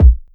Kick (Aint Got Time)(1).wav